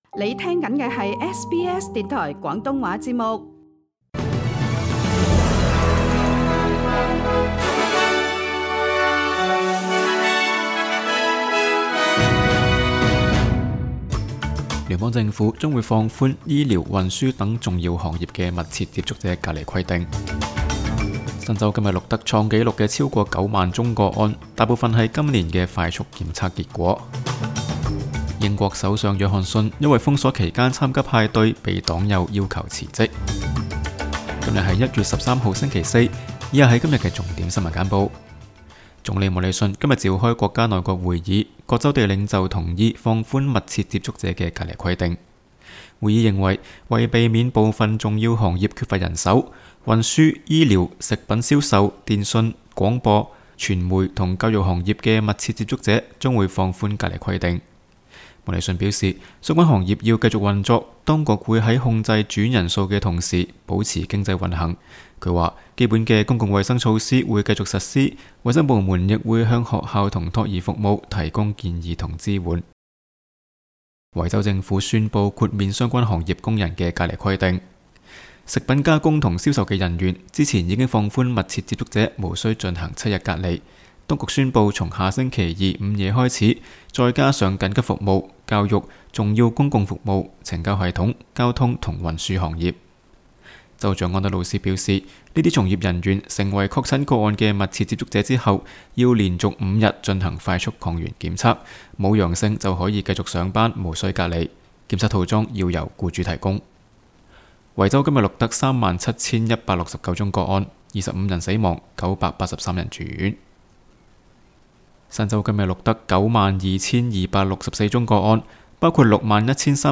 SBS 新闻简报（1月13日）